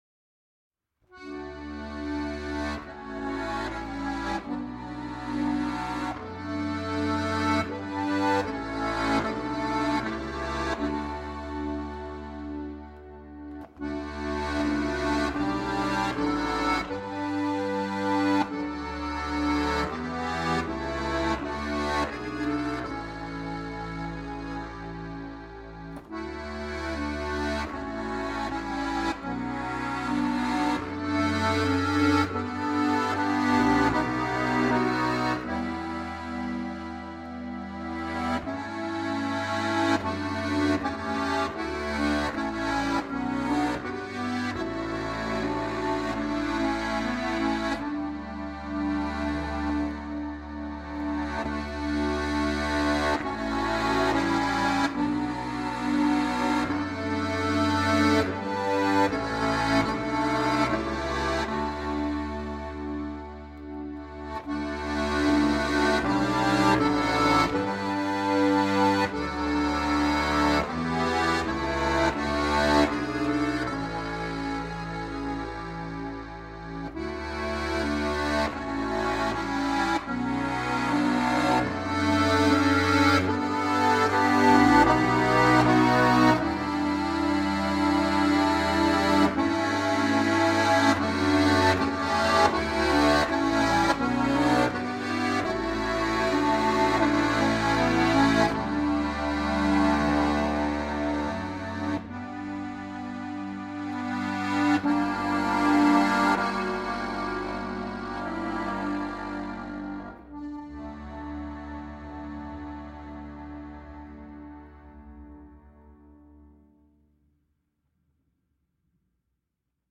Ein bekanntes englisches Kirchenlied!
• 4-reihige Harmonika